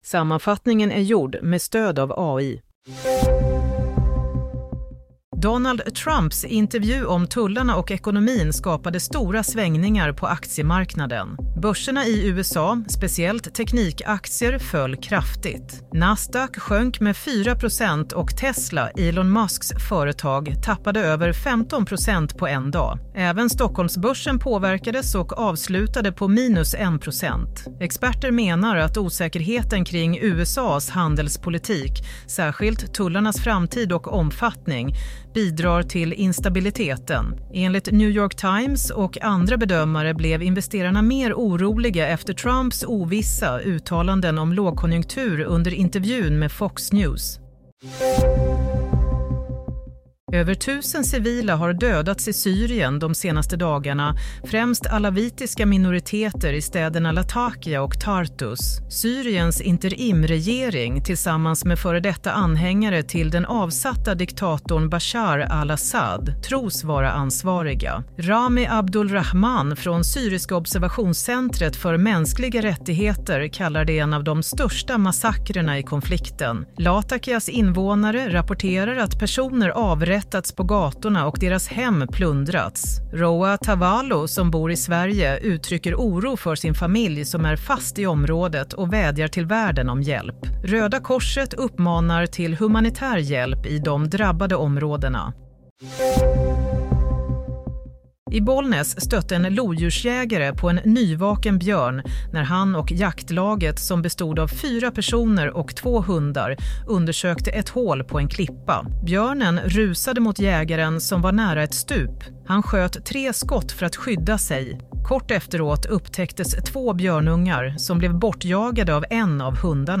Nyhetssammanfattning – 11 mars 07:00